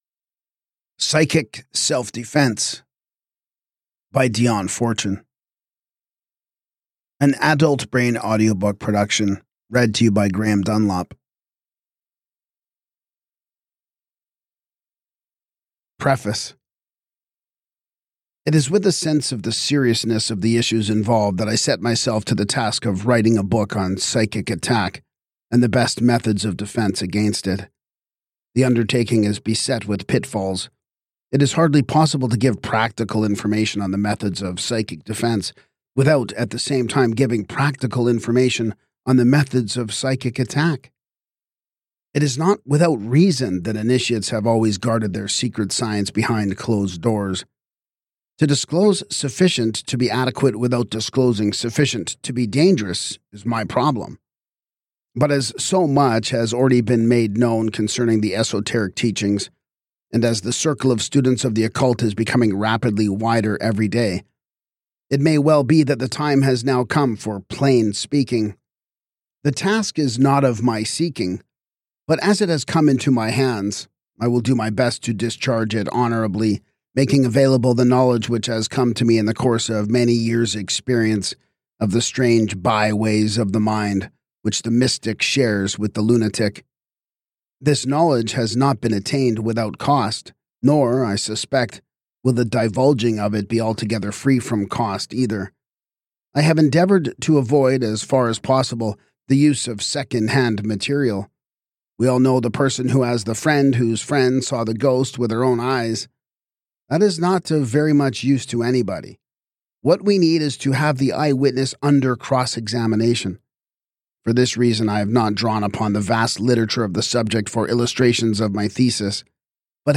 This audiobook edition brings Fortune’s clear, authoritative voice to life for modern listeners interested in occult studies, metaphysical psychology, spiritual protection, and the historical roots of contemporary psychic self-defense practices.